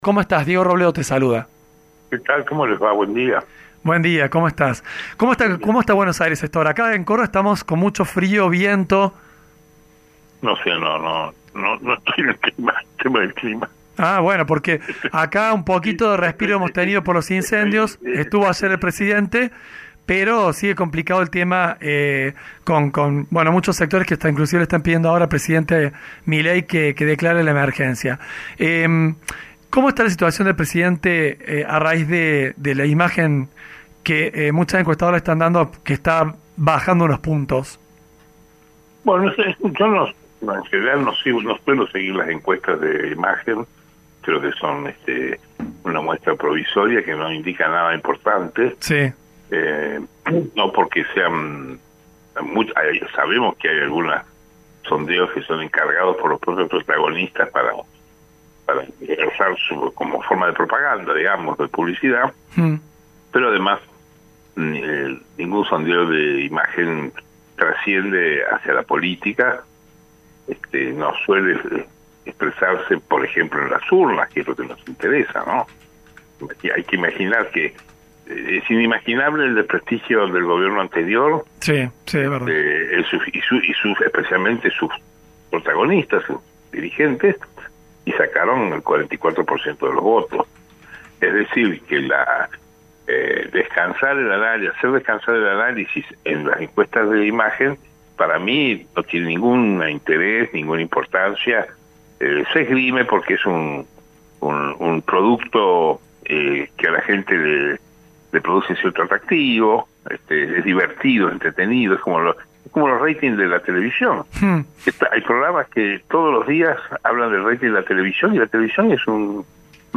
EL PAPA SE HA LLEVADO MEJOR CON LOS GOBIERNOS NO PERONISTAS (Un diálogo por radio)